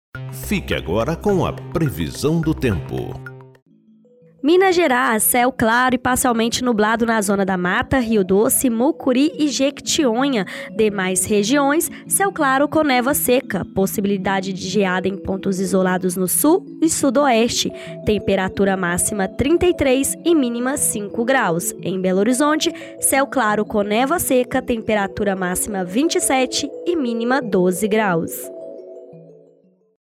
AMIRT Boletins Diários Destaque Minas Gerais Previsão do Tempo Rádio e TelevisãoThe estimated reading time is less than a minute